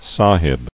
[sa·hib]